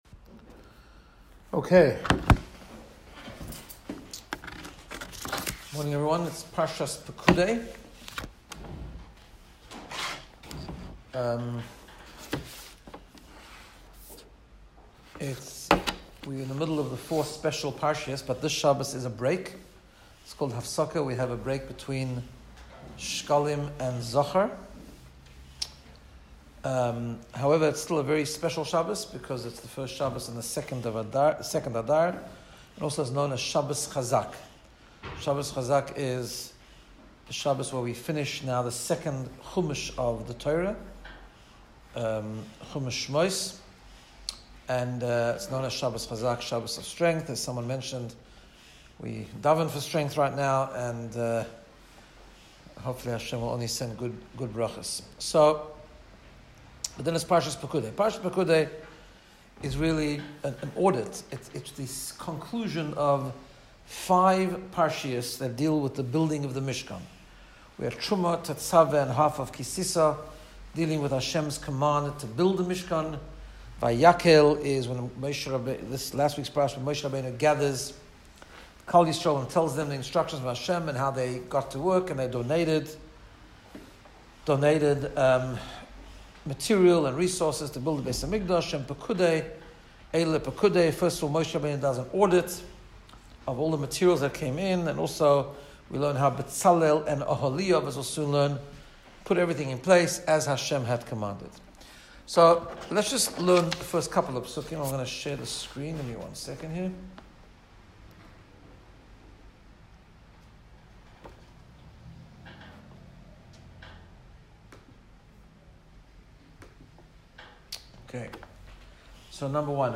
Shiur 5774